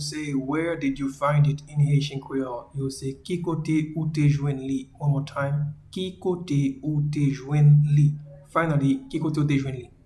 Pronunciation:
Where-did-you-find-it-in-Haitian-Creole-Ki-kote-ou-te-jwenn-li.mp3